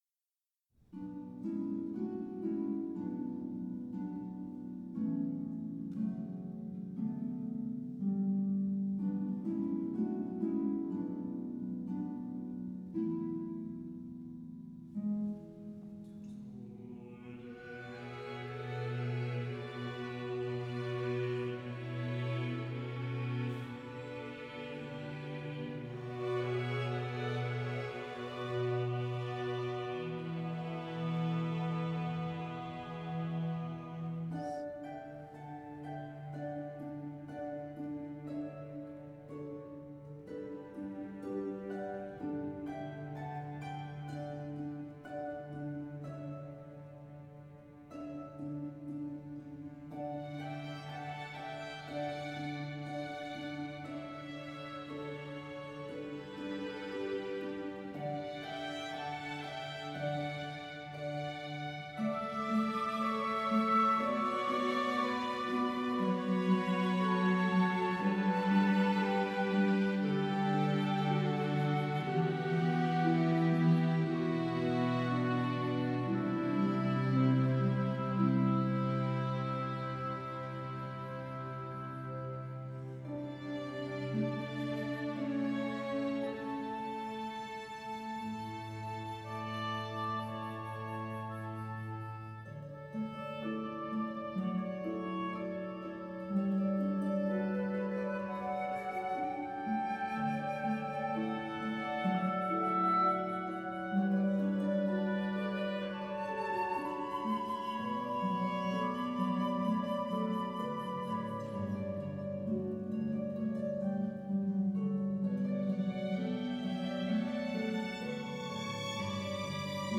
Genere: Choral.